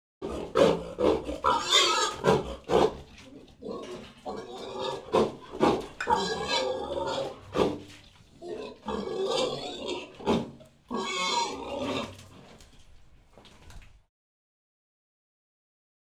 Index of /90_sSampleCDs/E-MU Producer Series Vol. 3 – Hollywood Sound Effects/Water/Pigs